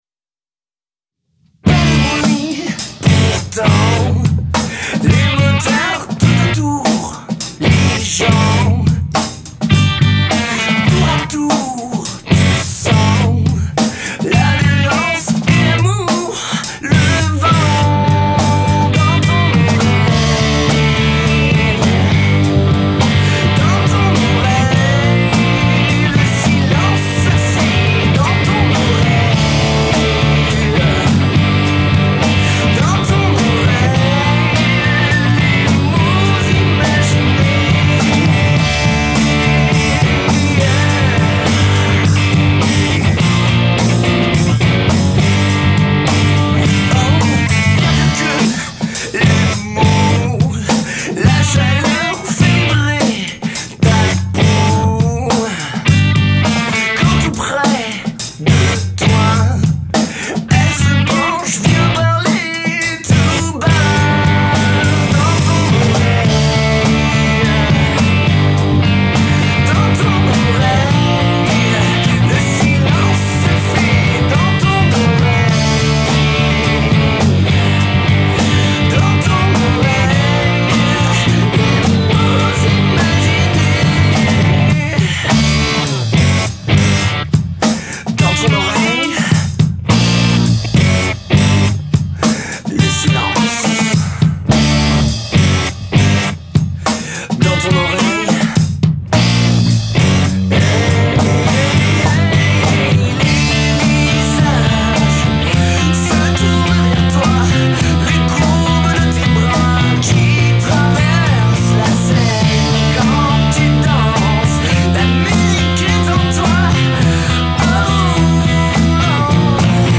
Basse
Batterie
Guitare - Chant